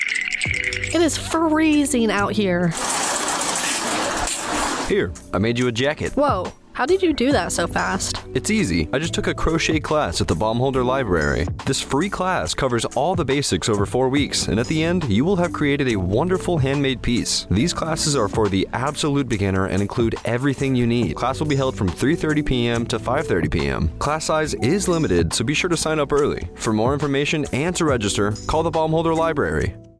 Radio Spot - Beginner Crochet Classes